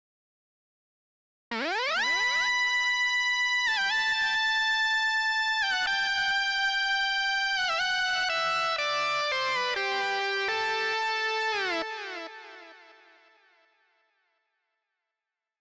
08 Synth Solo.wav